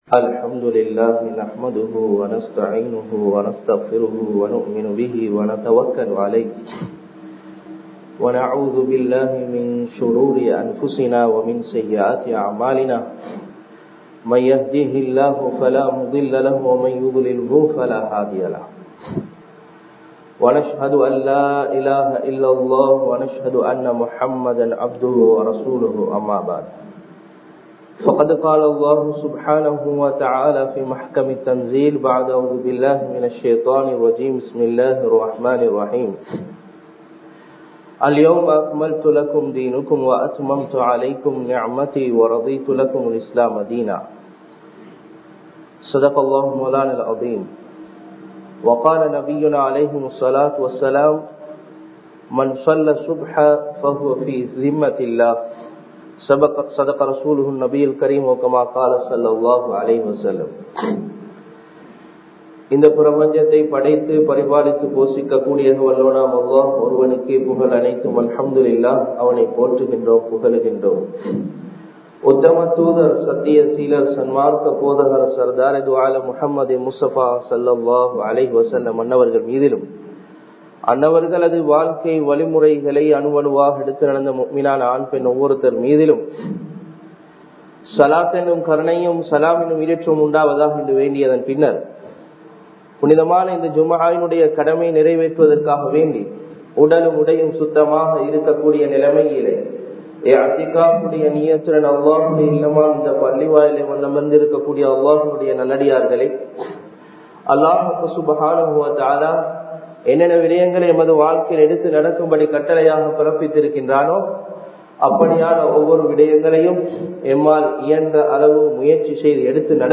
அல்லாஹ்வின் உதவி | Audio Bayans | All Ceylon Muslim Youth Community | Addalaichenai